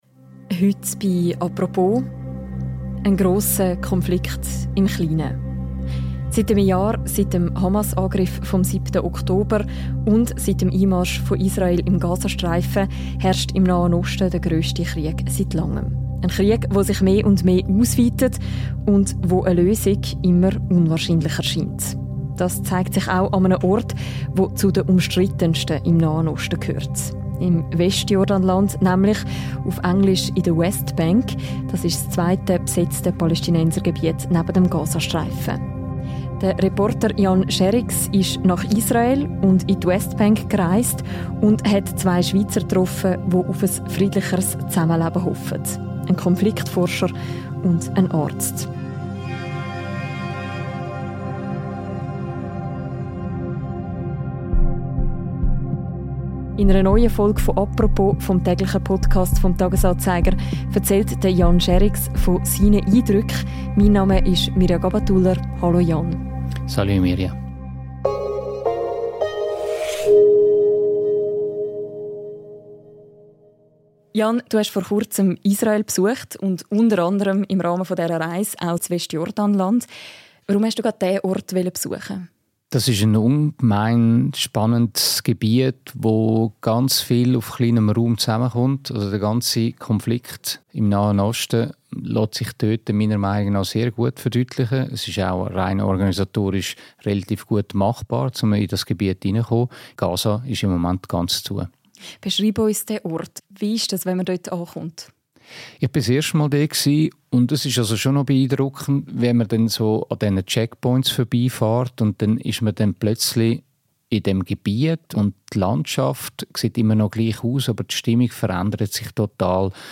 Im Westjordanland wird um jeden Flecken Land gekämpft. Zwei Schweizer engagieren sich im Krisengebiet für ein friedlicheres Zusammenleben. Eine Reportage.